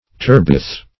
Turbeth \Tur"beth\ (t[^u]r"b[e^]th), n.